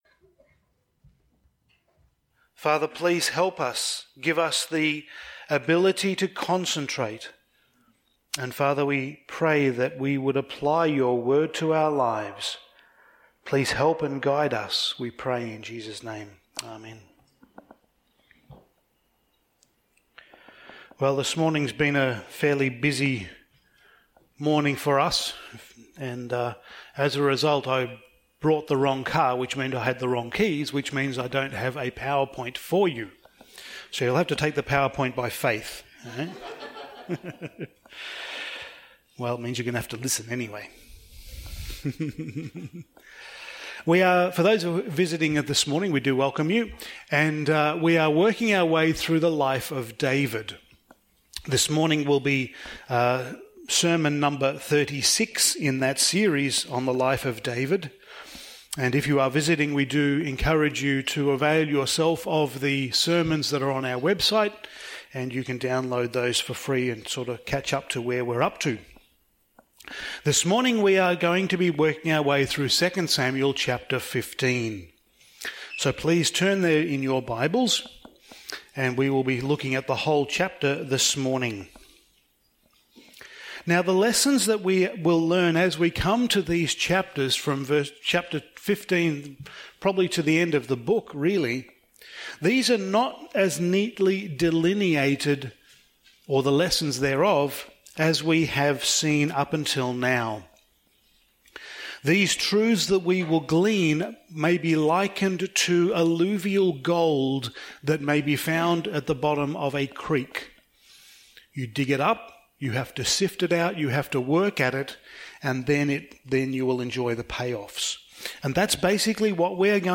Passage: 2 Samuel 15:1-37 Service Type: Sunday Morning